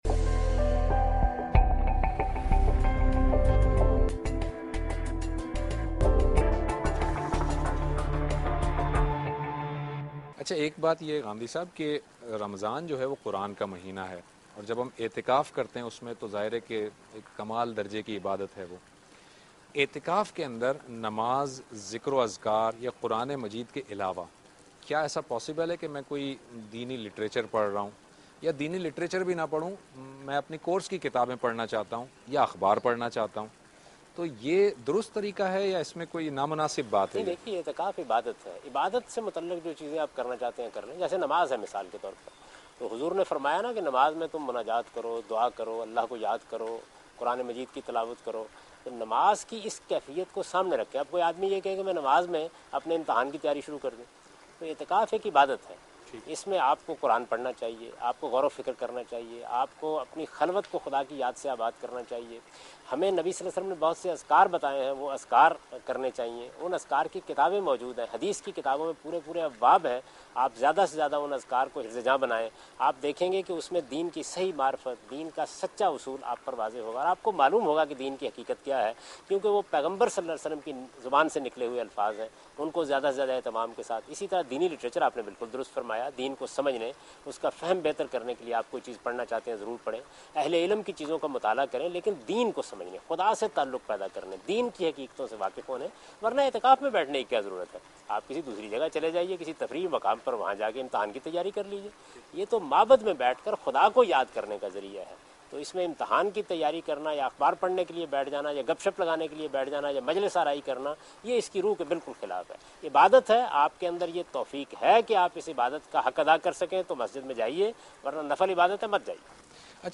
Category: TV Programs / Dunya News / Deen-o-Daanish /
دنیا ٹی وی کے پروگرام دین ودانش میں جاوید احمد غامدی اعتکاف اور عقیدت کے سربراہی کے متعلق گفتگو کر رہے ہیں